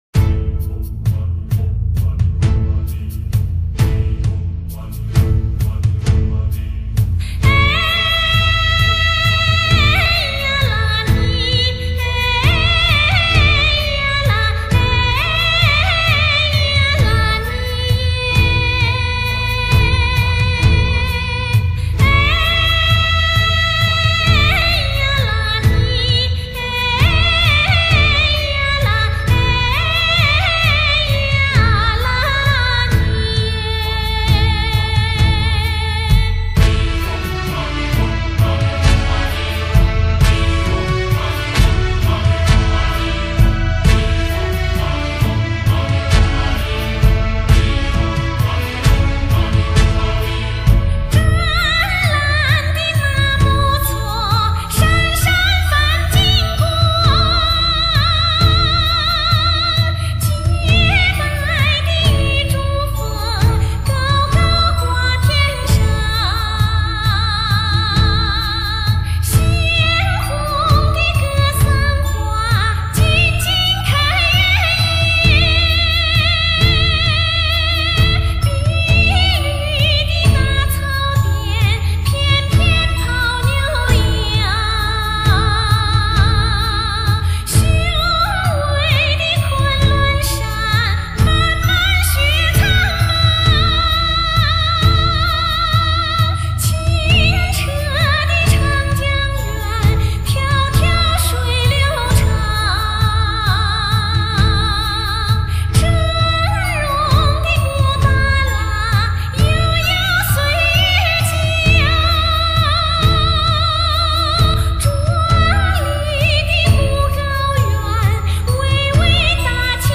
专业:歌舞
现为国家一级演员，是成都军区政治部战旗歌舞团独唱演员，四川省青联委员、四川省人大代表。
[mjh4][light]那歌声...那旋律...悠扬飘荡...[/light][/mjh4]